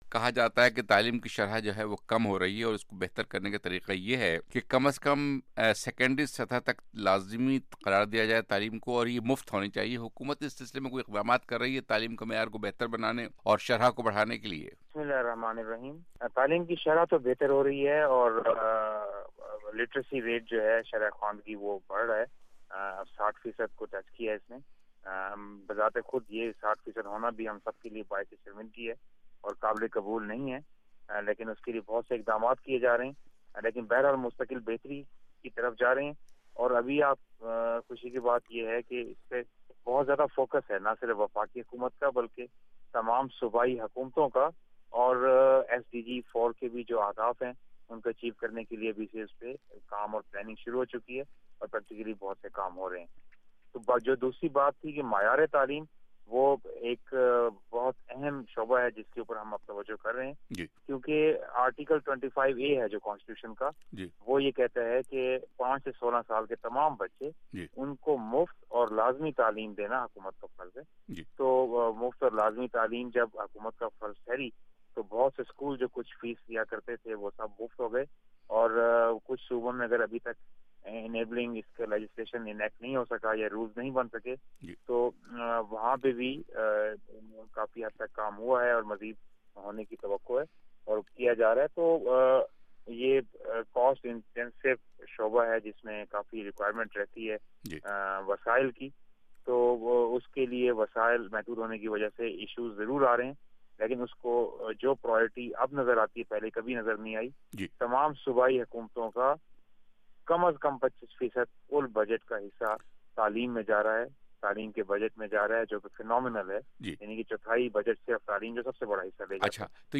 وزیر مملکت برائے تعلیم بلیغ الرحمن کا انٹرویو